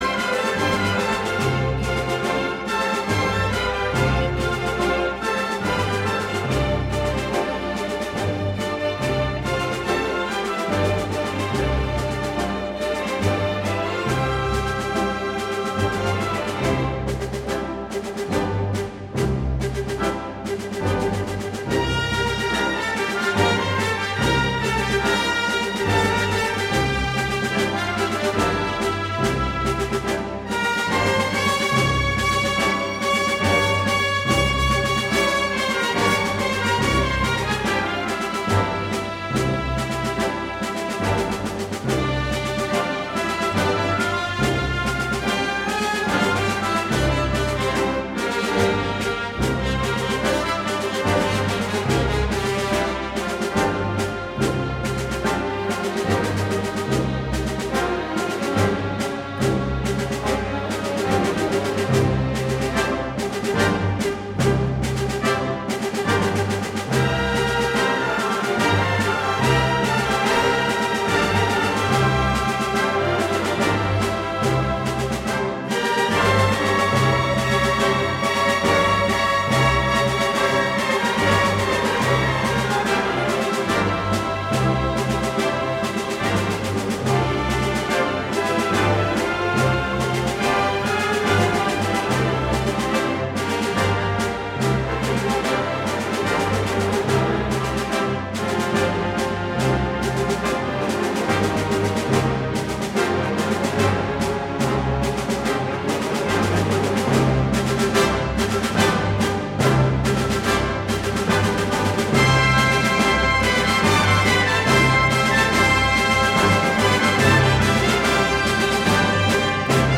拉威尔的《布莱罗舞曲》不是西班牙音乐。它是一部抽象的作品，强烈而激动，其中我们可以听到一个东方旋律和无情节奏的对抗。